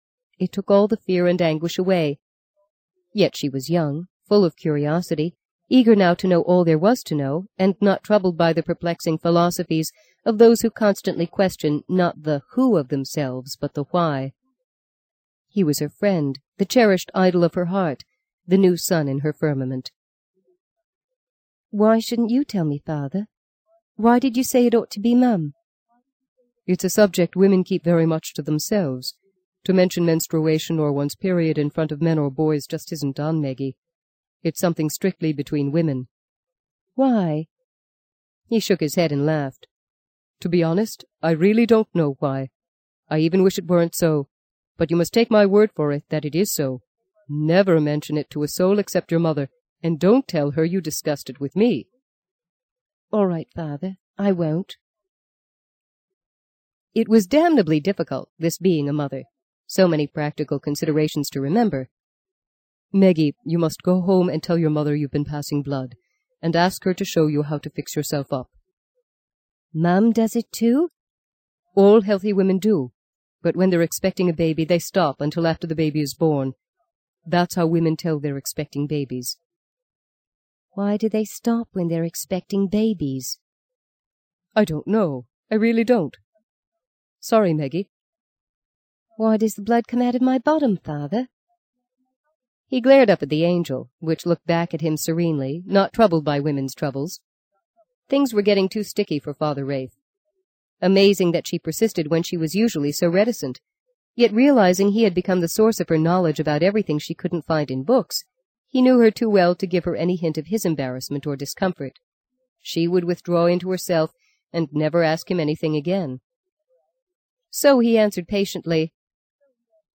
在线英语听力室【荆棘鸟】第六章 25的听力文件下载,荆棘鸟—双语有声读物—听力教程—英语听力—在线英语听力室